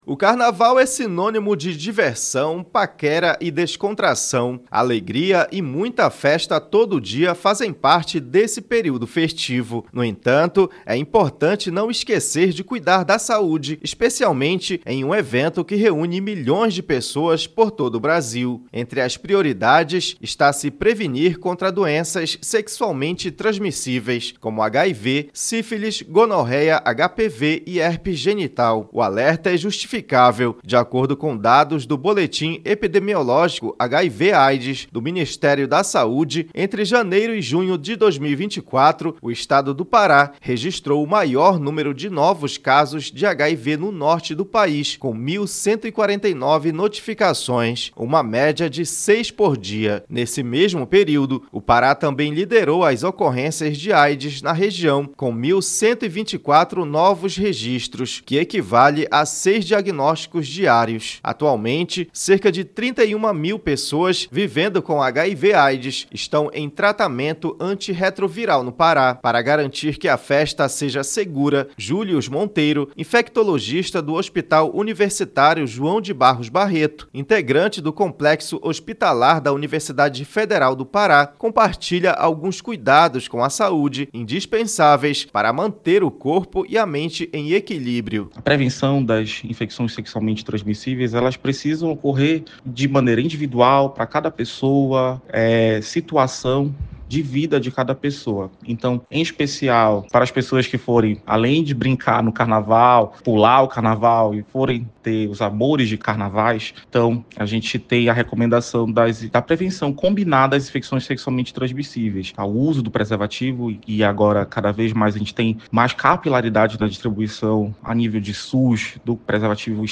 Acompanhe a reportagem com médicos do Hospital Barros Barreto, de Belém.